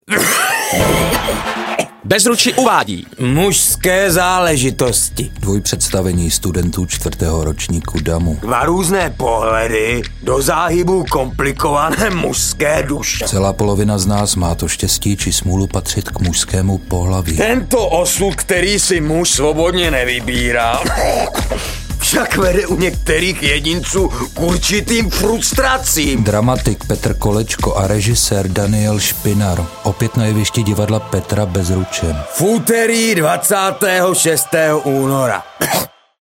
audioupout�vka